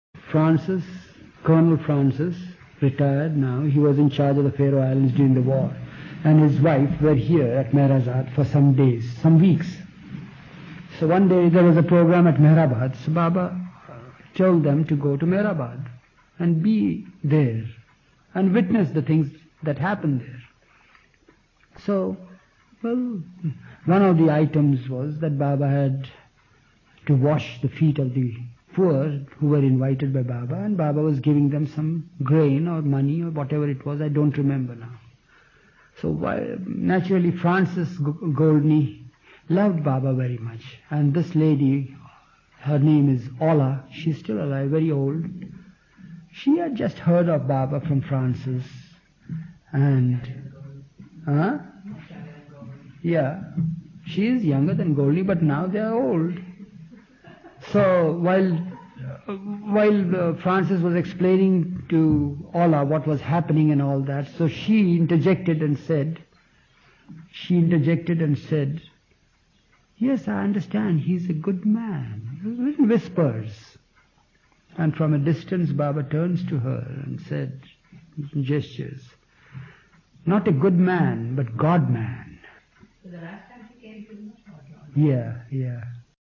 A collection of talks, stories, discussions and musical performances by Avatar Meher Baba's mandali and lovers, recorded primarily in Mandali Hall, Meherazad, India.